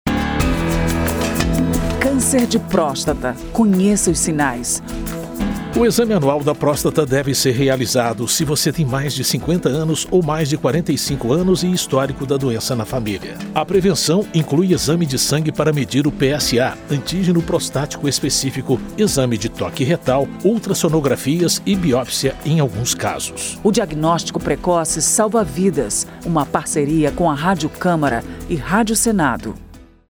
spot-cancer-de-prostata-03-parceiras.mp3